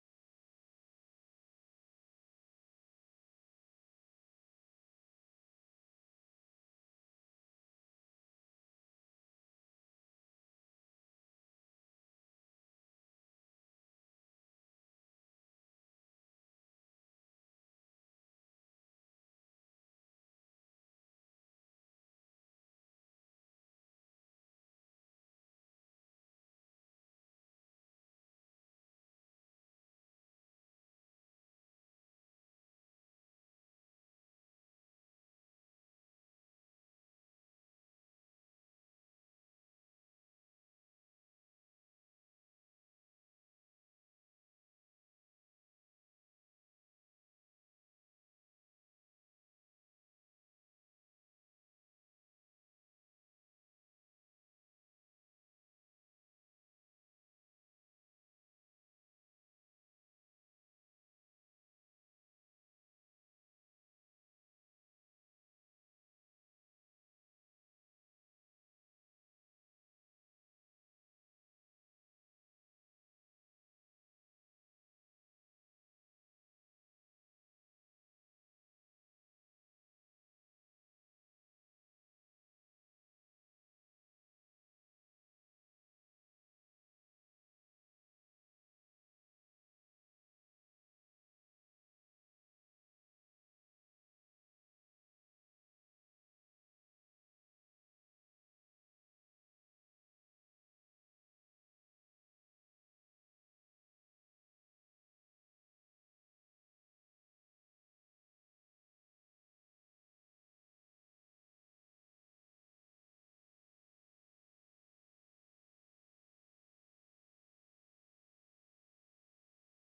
Wo: Rosgartenmuseum Konstanz